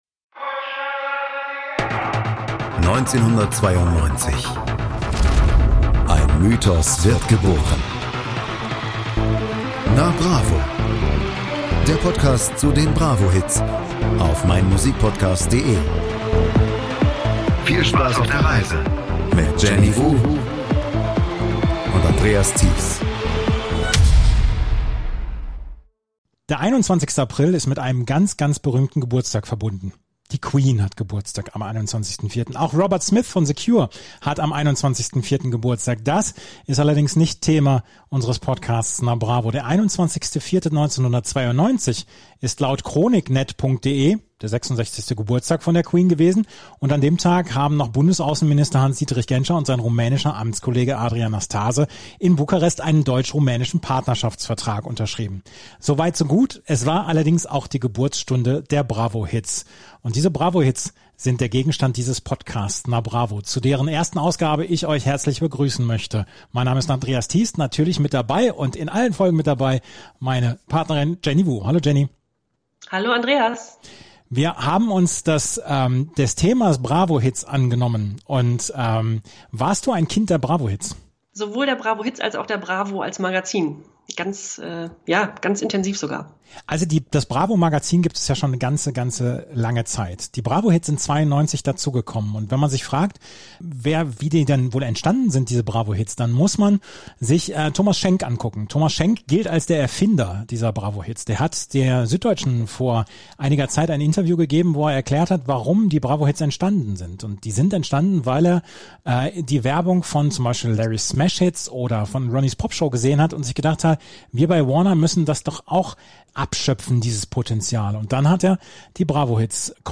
Dazu gibt es Anekdoten und unsere Erinnerungen zu den Künstlerinnen und Künstlern. Und um euch mit so manchem Ohrwurm zu entlassen, gibt es natürlich auch den einen oder anderen Soundschnipsel.